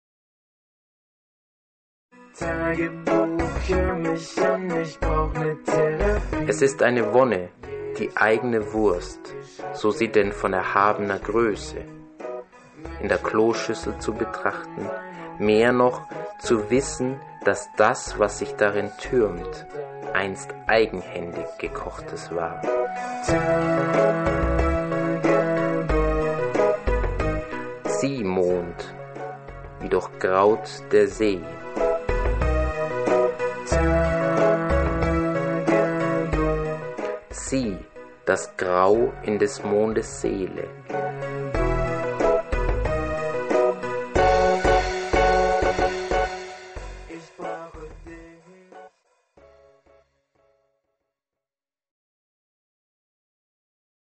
Quintessenzen Teil 1 (Hörbuch)